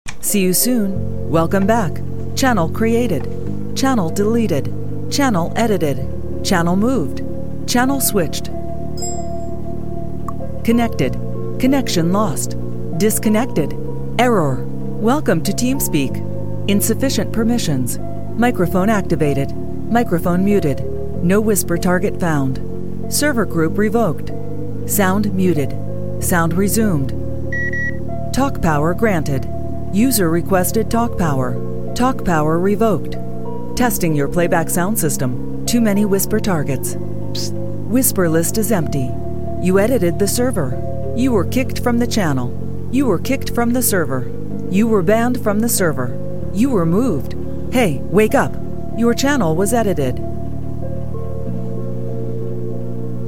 Sounds of Nostalgia: Teamspeak 3 sound effects free download